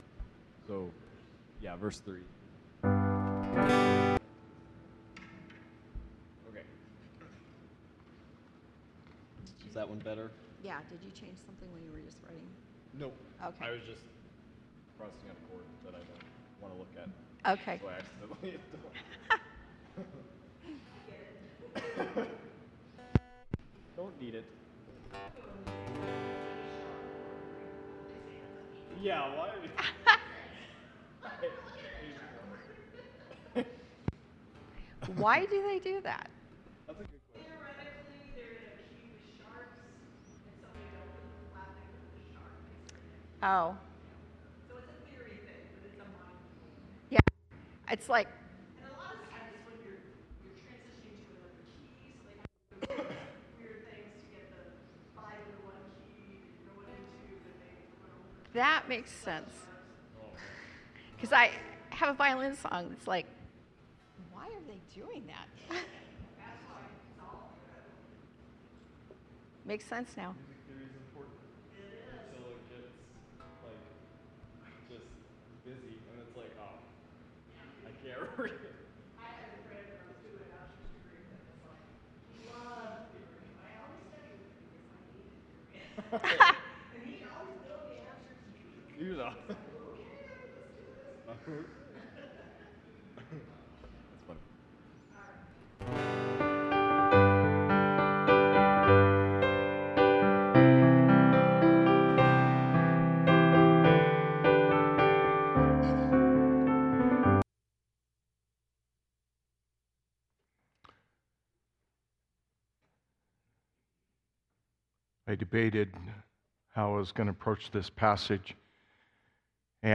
Sermons at New Life Christian Reformed Church